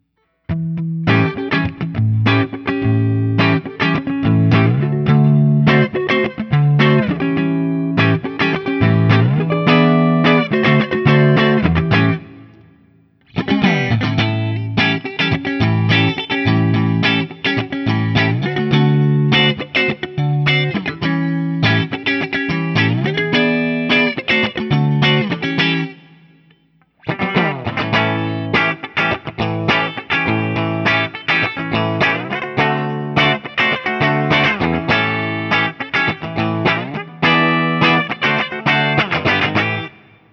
ODS100 Clean
7th Chords
I’ve had this guitar for some time and I had made the recordings using my Axe-FX II XL+ setup through the QSC K12 speaker recorded direct into my Macbook Pro using Audacity.
For the first few recordings I cycled through the neck pickup, both pickups, and finally the bridge pickup.